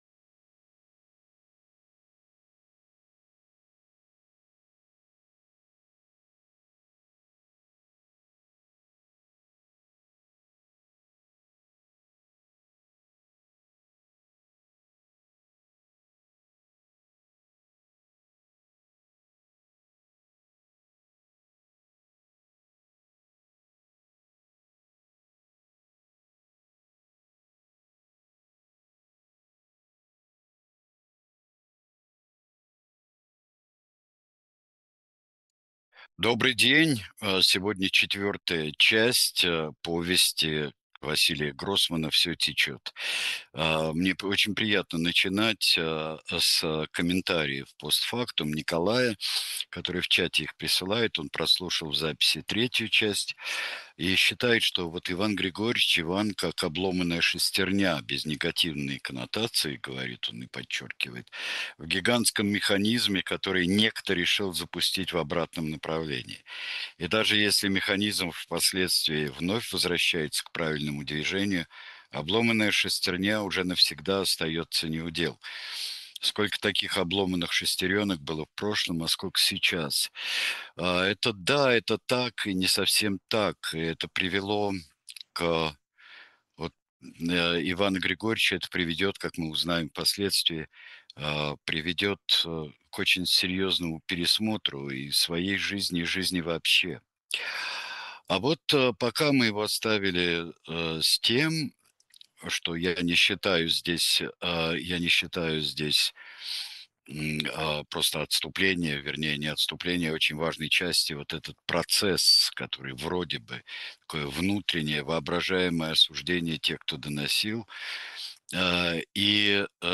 Читает Сергей Бунтман
vsyo-techyot-vasiliya-grossmana.-chast-4.-chitaet-sergej-buntman.mp3